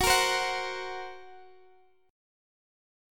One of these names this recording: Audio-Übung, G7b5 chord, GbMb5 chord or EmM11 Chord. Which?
GbMb5 chord